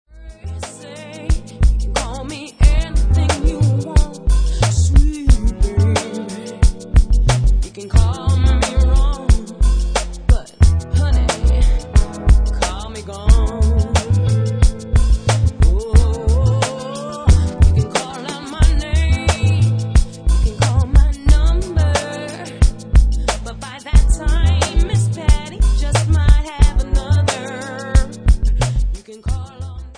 w/Vocal